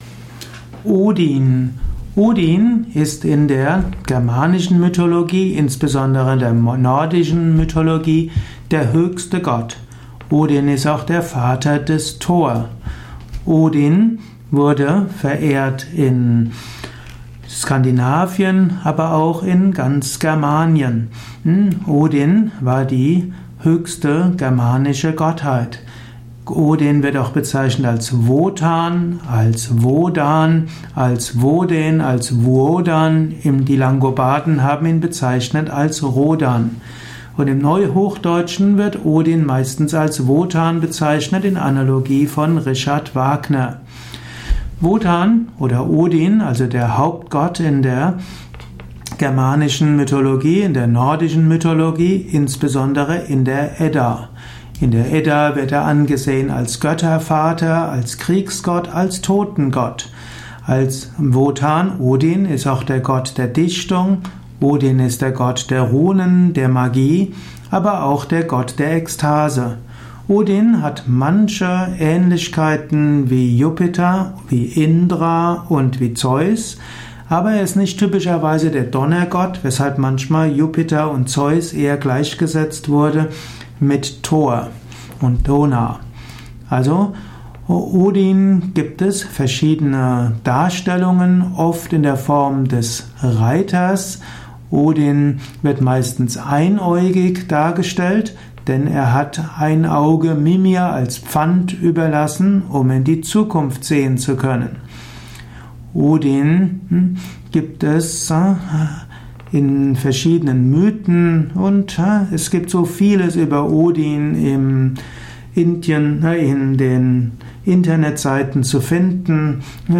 Mit Überlegungen über die Bedeutung von Odin in der germanischen Mythologie, im germanischen Pantheon. Welche Bedeutung hat Gott Odin vielleicht sogar in der heutigen Zeit? Dies ist die Tonspur eines Videos, zu finden im Yoga Wiki.